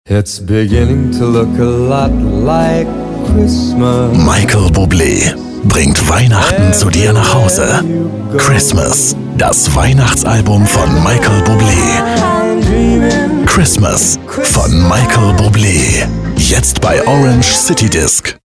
MichaelL Buble TV - Christmas.mp3
Middle Aged